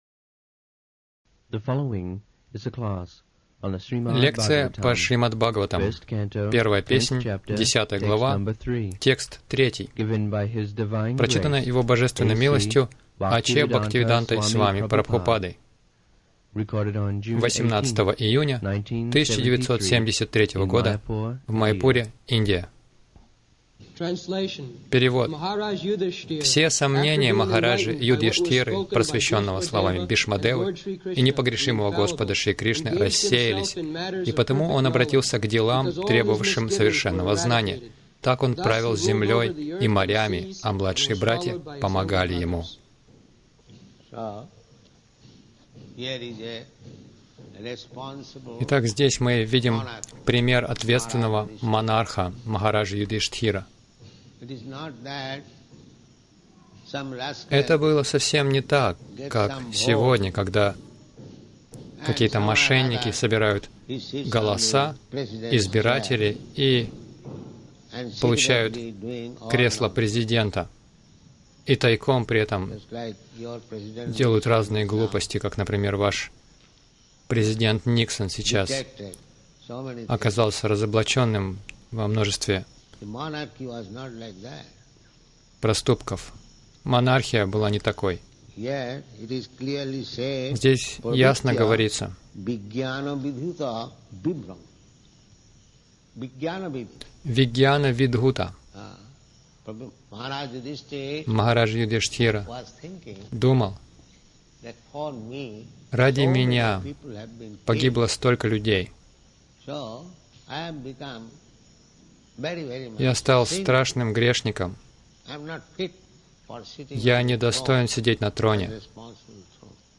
Милость Прабхупады Аудиолекции и книги 18.06.1973 Шримад Бхагаватам | Маяпур ШБ 01.10.03 — Богосознающая Монархия Загрузка...